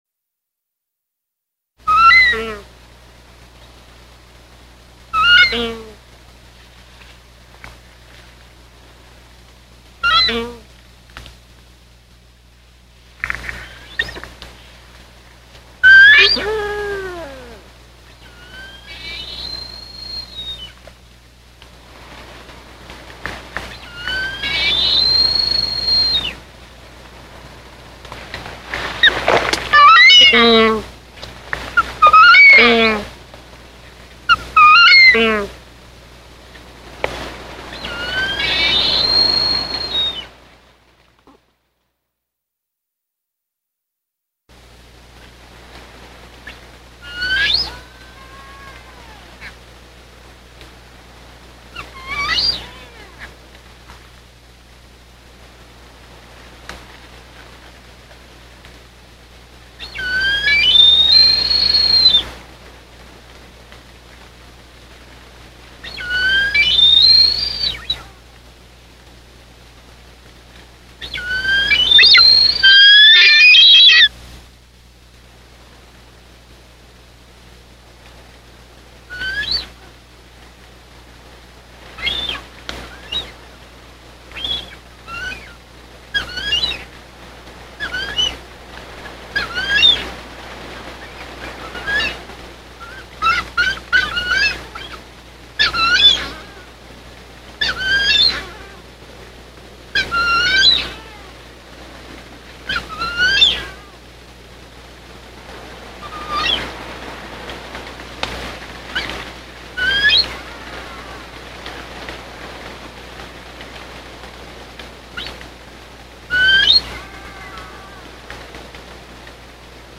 The 3000' foot summit of the island of Ta'u is Mt. Lata, a vine and tree fern forest whipped by wind, fog and rain.
Before darkness settles in, cicadas pulse a busy-signal before quieting for the night. Fruit bats and wattles honeyeaters are also voices in the forest. After dark, Tahiti petrels, large black and white seabirds, return from feeding at sea and fly around Mt. Lata before landing and visiting their nests under the roots of the dense vegetation. Audubon shearwaters also join the choir of petrel whistles and shrieks.
Recorded in Dec. 2001 and 2002, using a parabolic dish to amplify calls below the cliff. Surf and wind noise have been filtered out so the birds calls can be more readily hear.